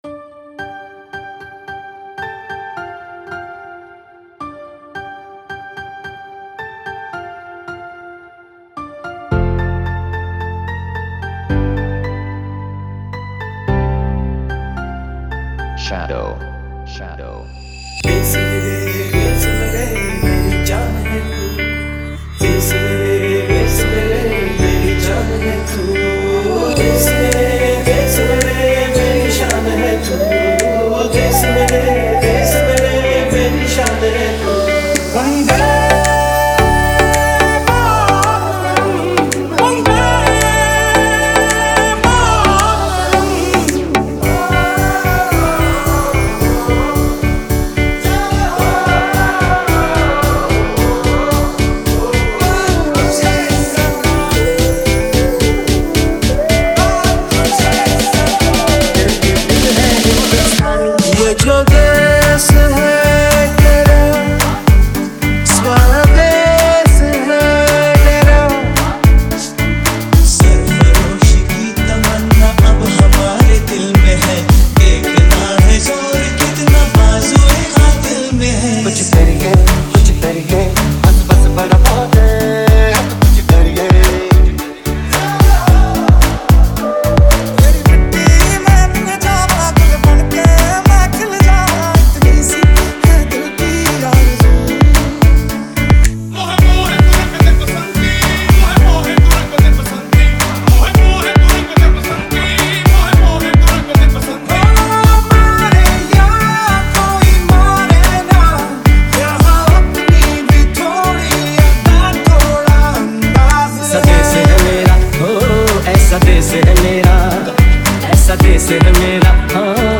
Desh Bhakti Dj Remix Song